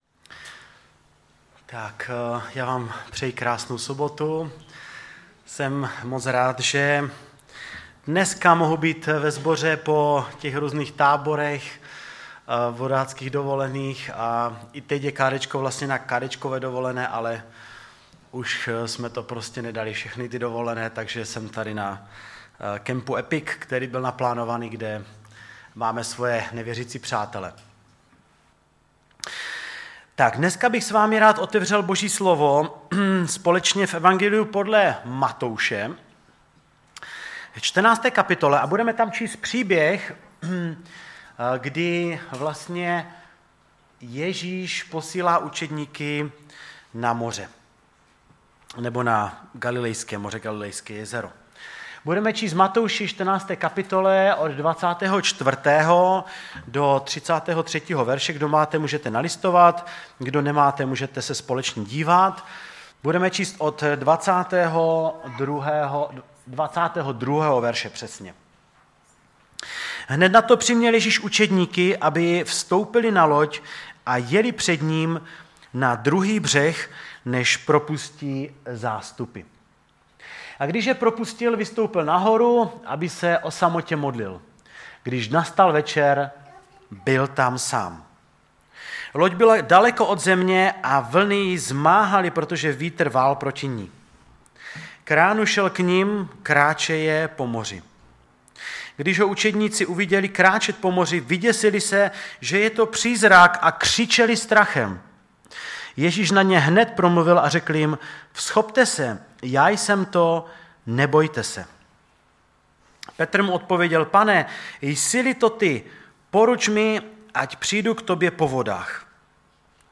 Kázání
Kazatel